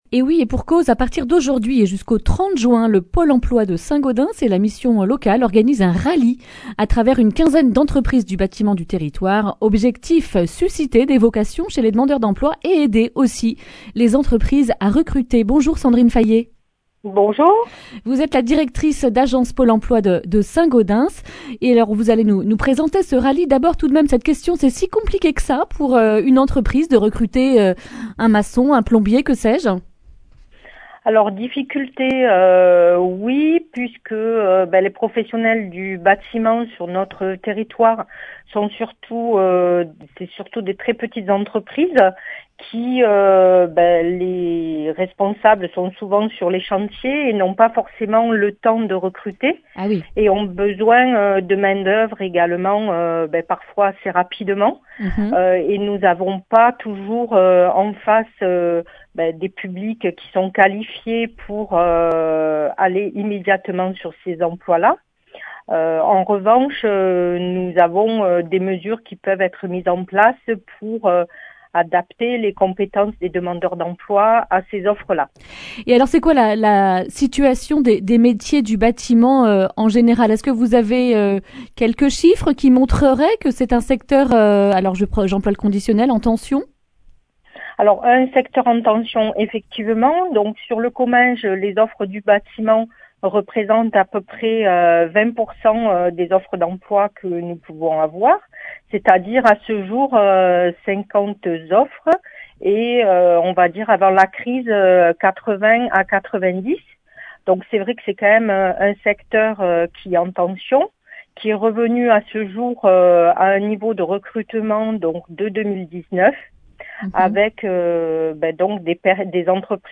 jeudi 18 mars 2021 Le grand entretien Durée 11 min